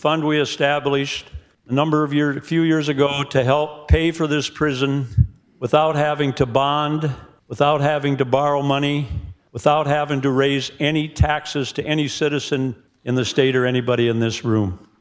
Bill supporter, Republican Rep. Jack Kolbeck from Sioux Falls, explains the funding process.